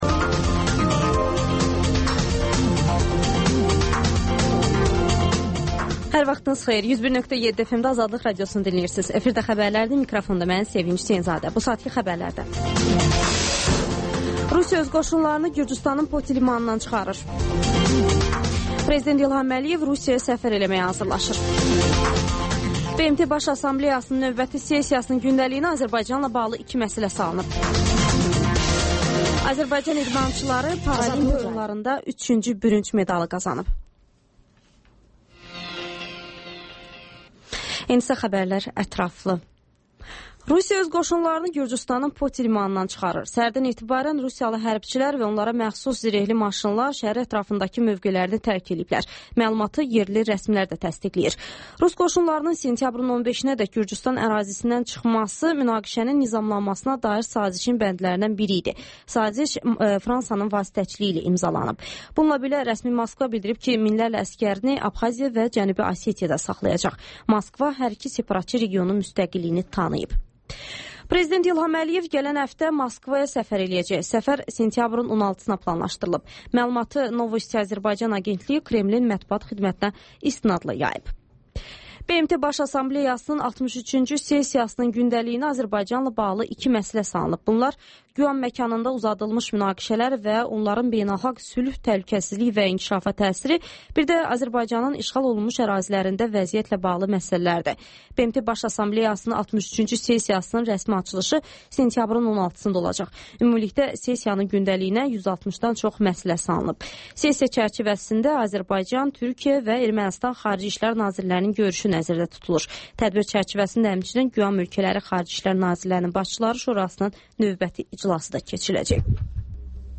Xəbərlər, QAYNAR XƏTT: Dinləyici şikayətləri əsasında hazırlanmış veriliş, sonda MÜXBİR SAATI